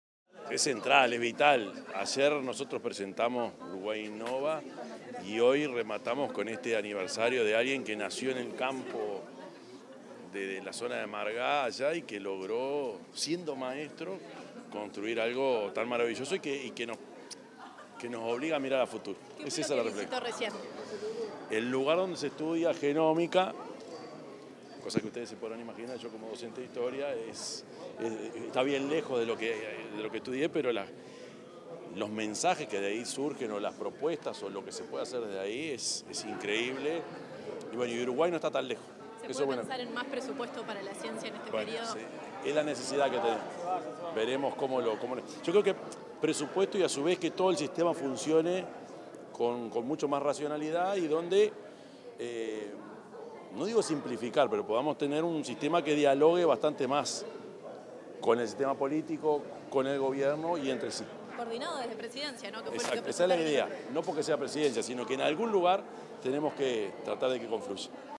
El presidente de la República, profesor Yamandú Orsi, brindó declaraciones a la prensa, tras participar en la celebración del Día de la Investigación,